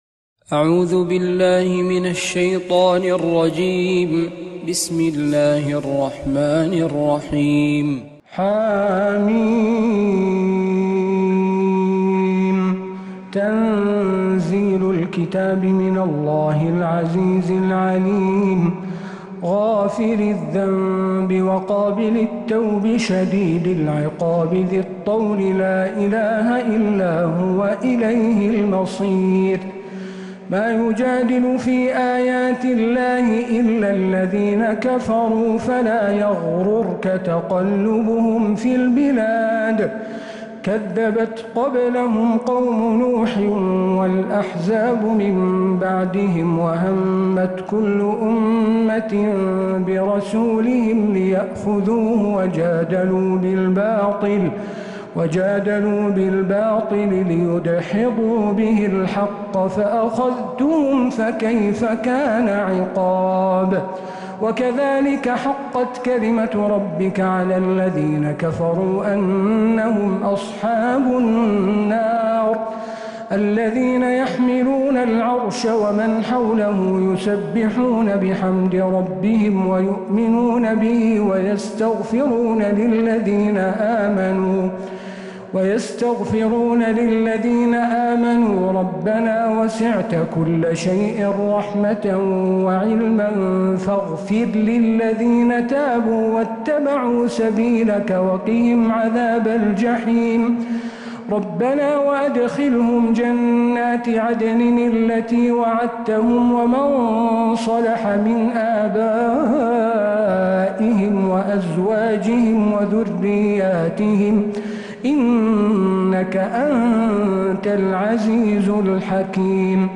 سورة غافر كاملة من الحرم النبوي